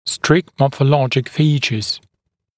[strɪkt ˌmɔːfə’lɔʤɪk ‘fiːʧəz][стрикт ˌмо:фэ’лоджик ‘фи:чэз]строгие морфологические признаки